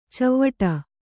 ♪ cavvaṭa